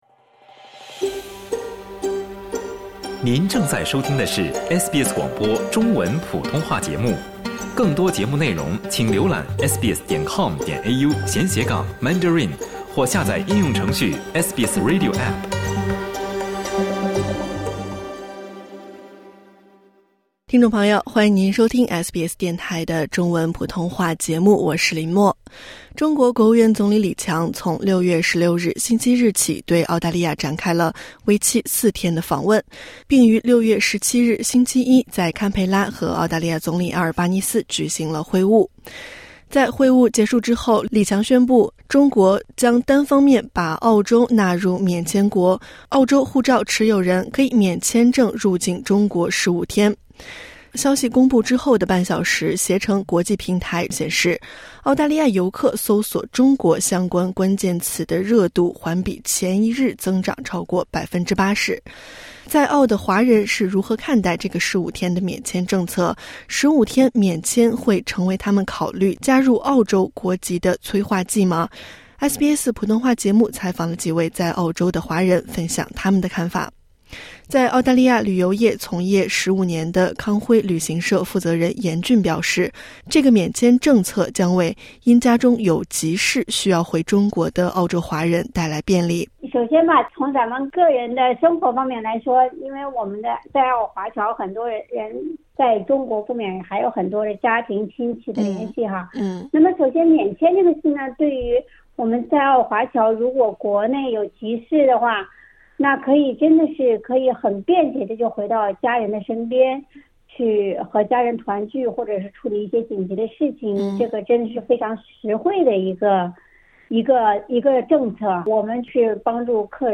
SBS普通话节目采访了几位在澳华人分享他们的看法。